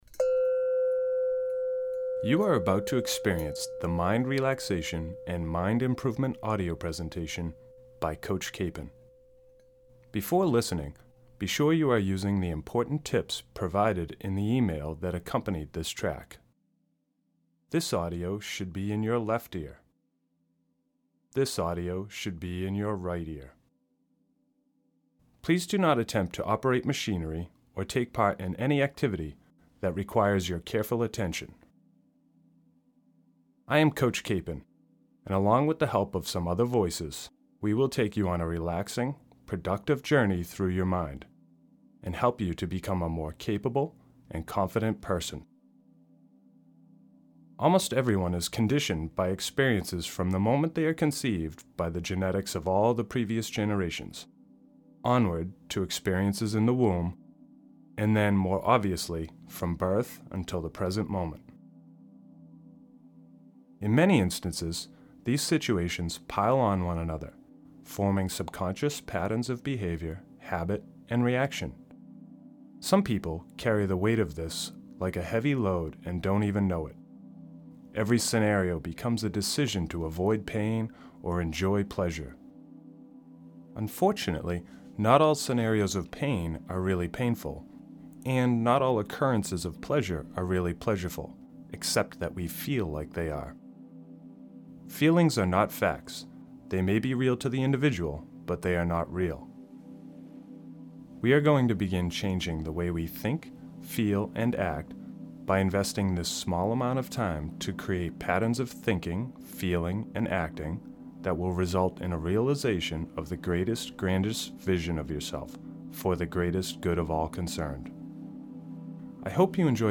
A guided meditation for better health.